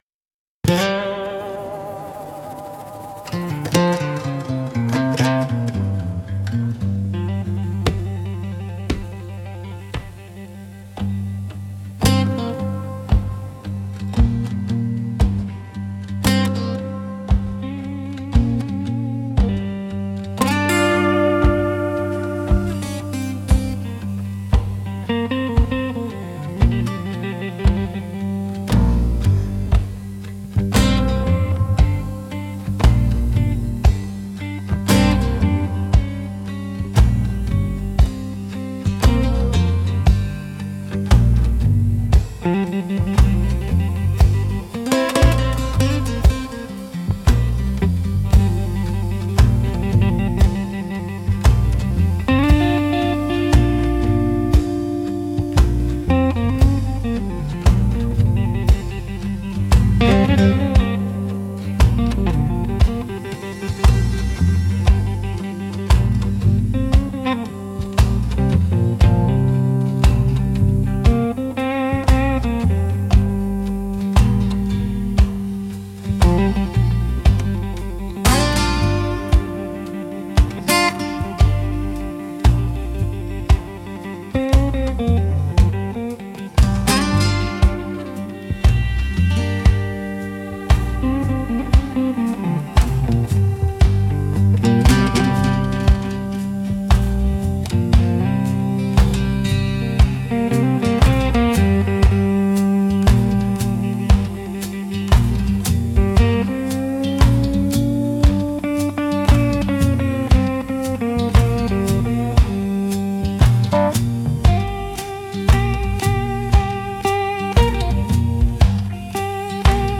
Instrumental - The Pull of the Old Road